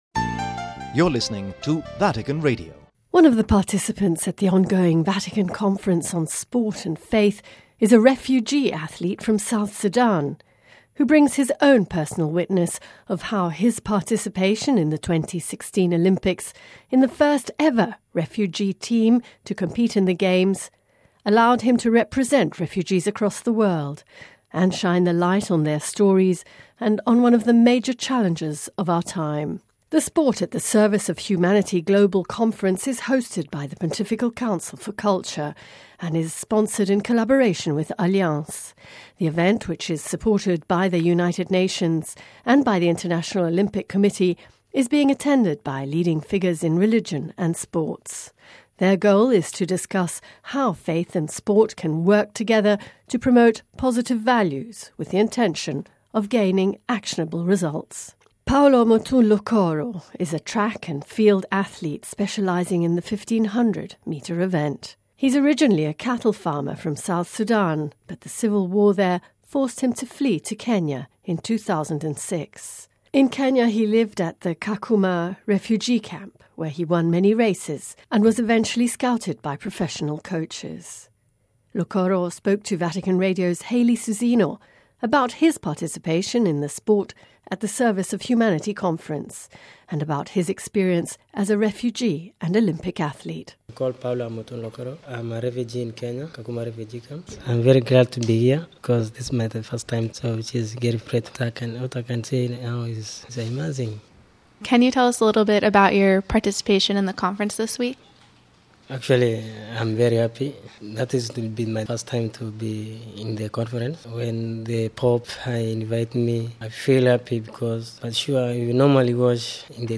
about his participation in the ‘Sport at the Service of Humanity’ Conference and his experiences as a refugee and Olympic athlete.